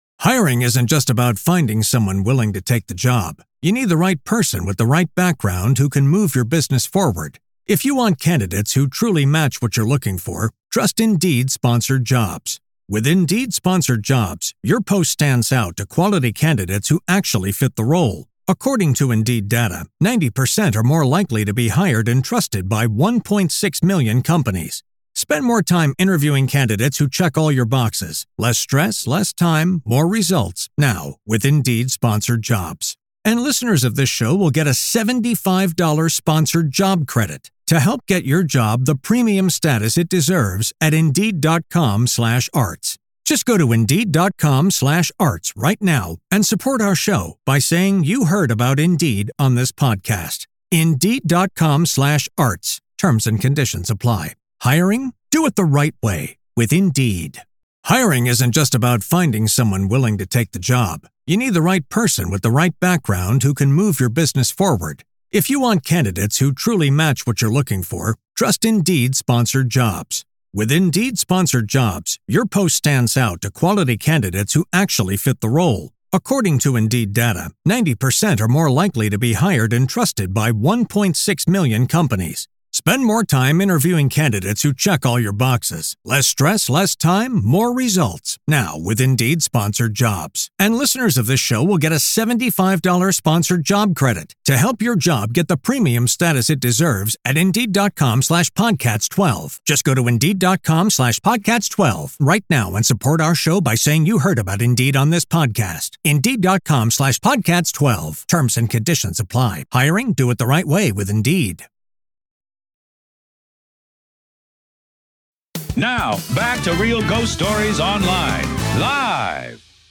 A caller shares his chilling encounter with a shadow cat that vanishes into walls—and his cousin sees the same thing.